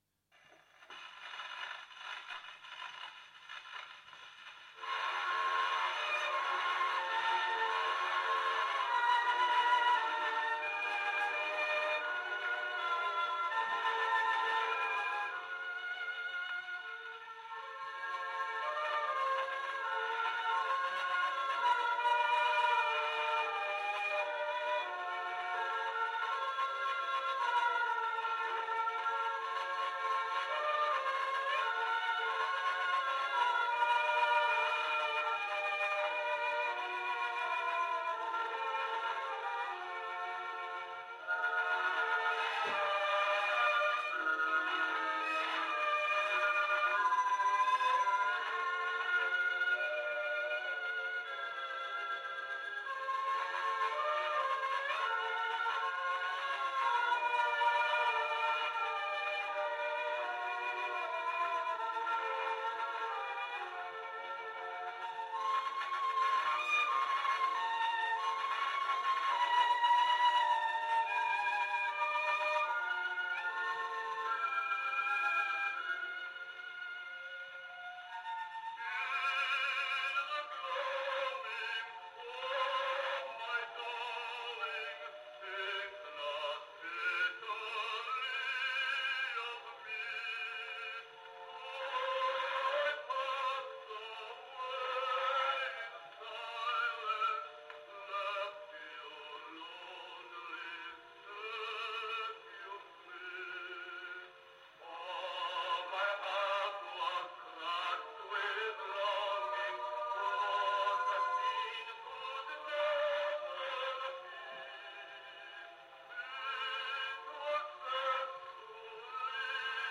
环境音 " 78转
描述：一台19世纪末的78机。
Tag: 78 民谣 留声机 硕士 留声机 播放 记录 声音